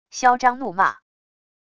嚣张怒骂wav音频